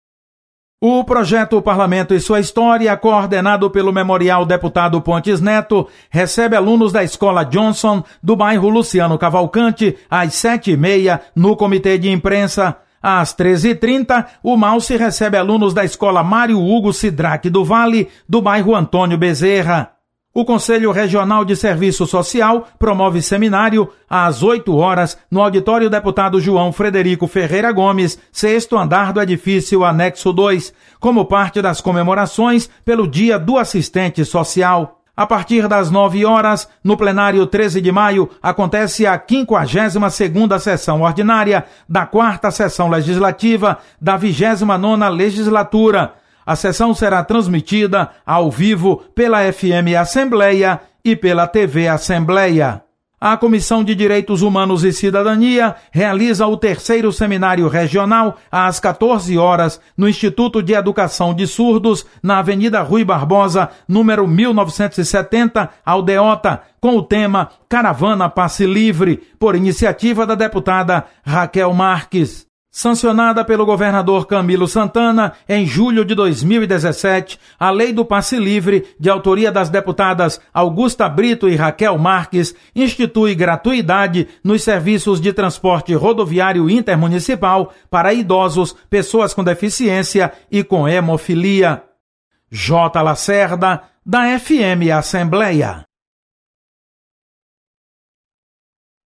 Acompanhe as atividades da Assembleia Legislativa desta terça-feira. Repórter